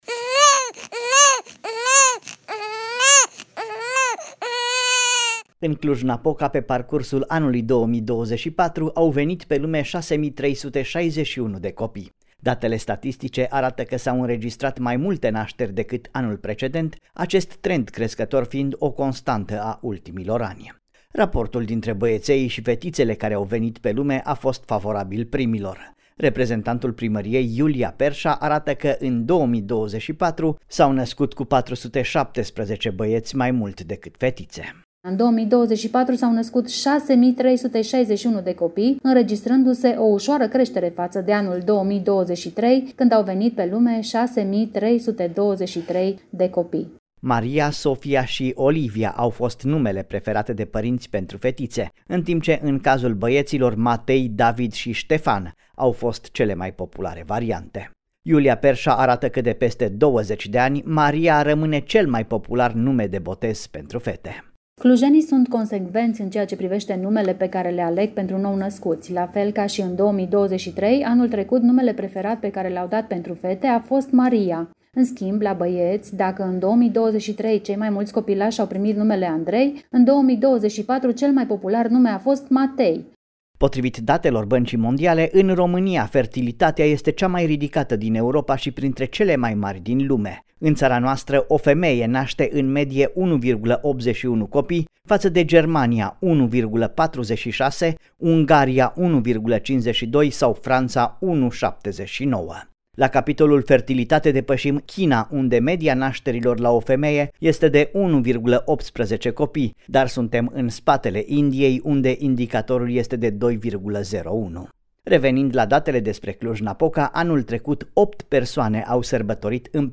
reportaj-nasteri-nume-copii.mp3